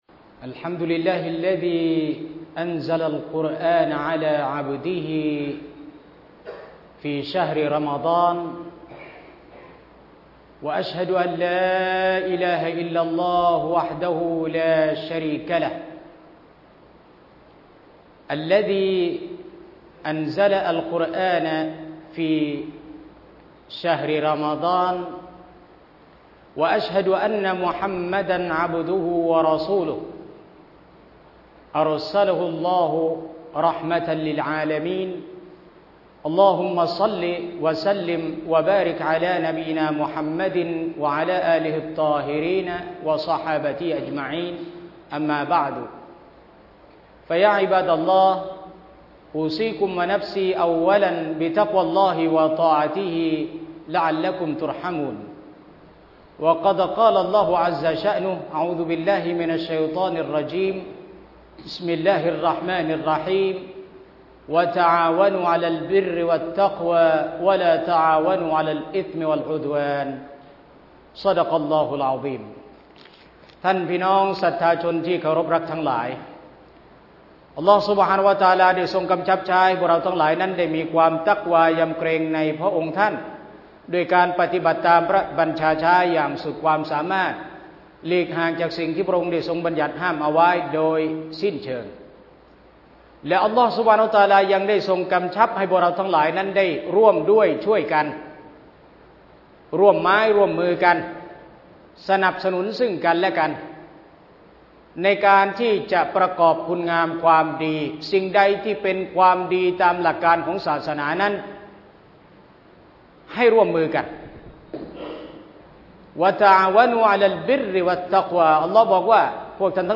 มัสยิดกลางจังหวัดชลบุรี ดาวน์โหลดไฟล์เสียง
คุตบะฮฺวันศุกร์ : การรักษาญะมาอะฮฺ